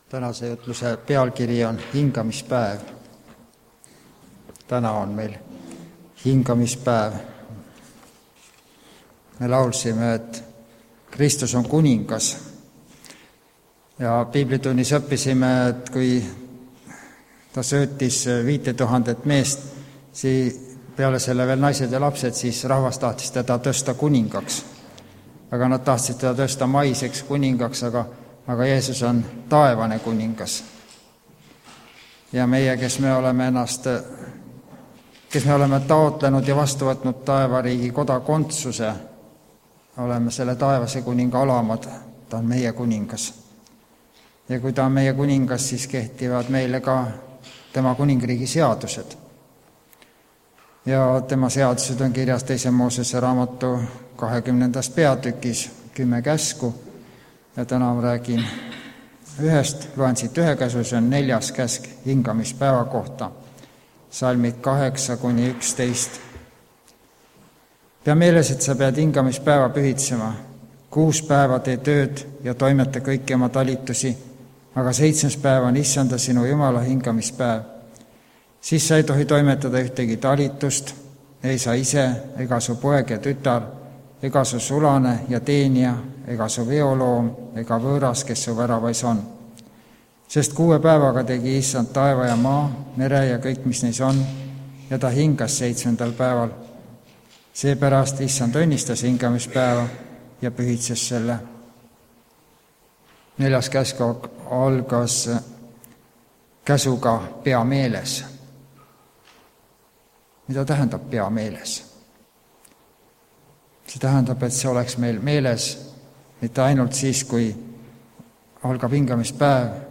Lauluhetk aga aastast 1983 Rakvere koor - Vaikne rahupäev
Jutlused